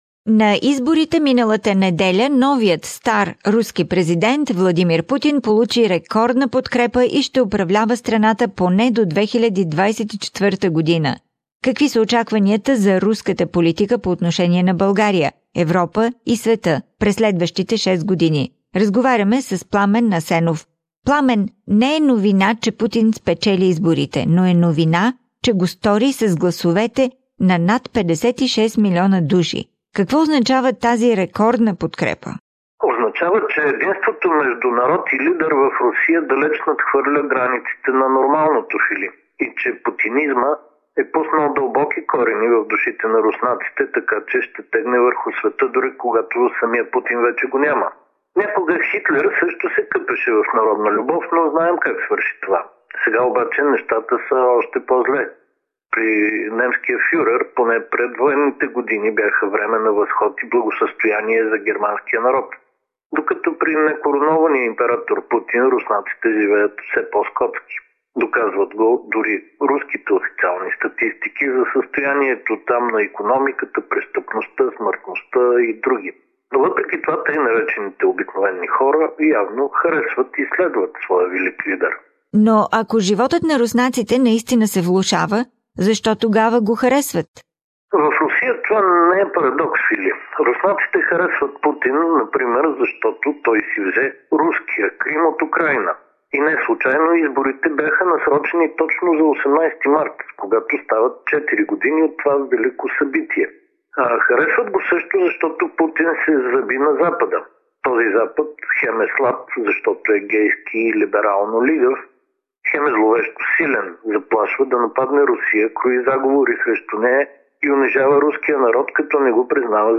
What does this record support mean and what are the expectations for Russian policy towards Bulgaria, Europe and the world over the next 6 years? Political Analysis